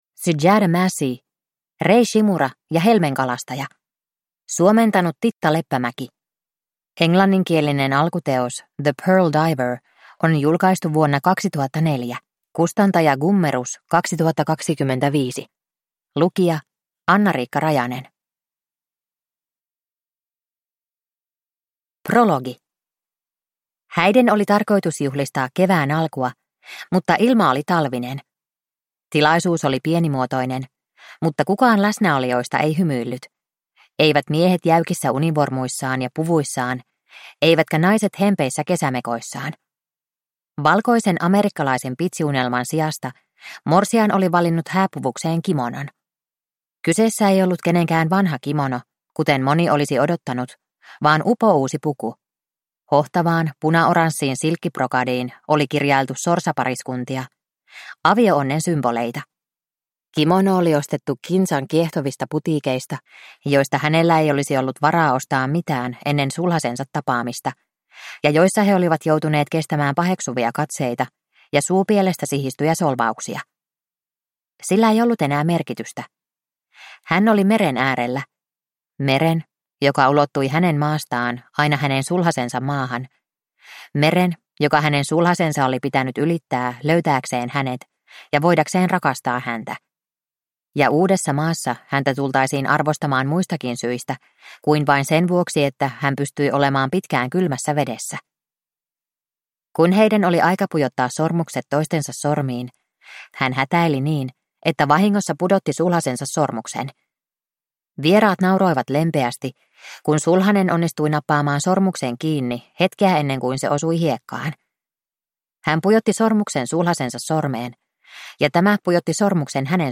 Rei Shimura ja helmenkalastaja (ljudbok) av Sujata Massey